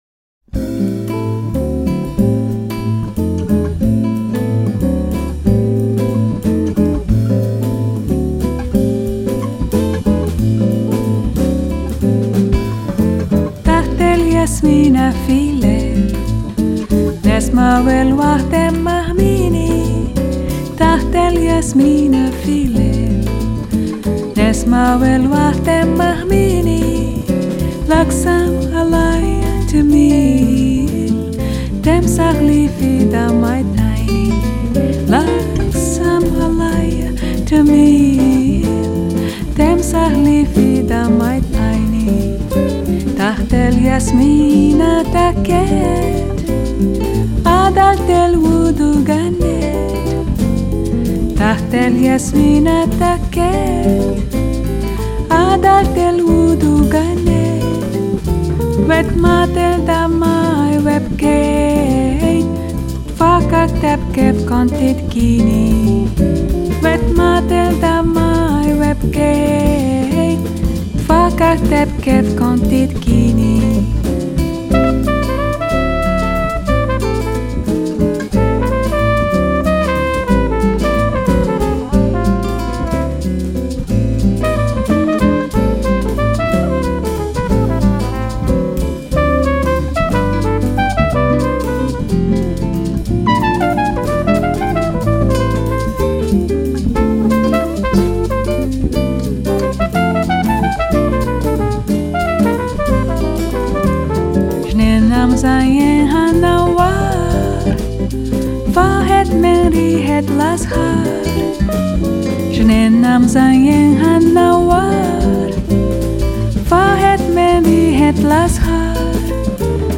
閒適怡人、無拘無束的音樂，搭配上她時而慵懶柔軟、時而活潑俏皮的唱腔，
音樂類型：爵士樂 - Bossa Nova[center]